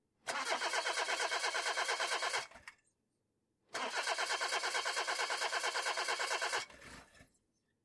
Звуки поломки автомобиля
Звук разряженного аккумулятора автомобиля